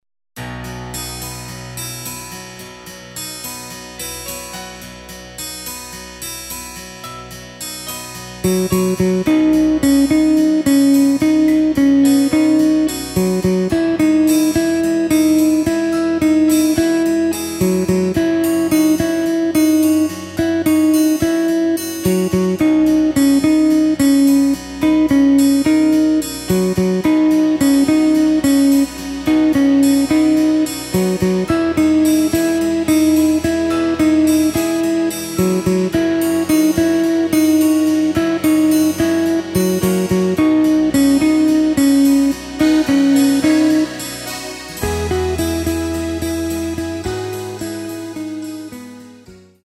Takt:          4/4
Tempo:         108.00
Tonart:            B
Ballade aus dem Jahr 2020!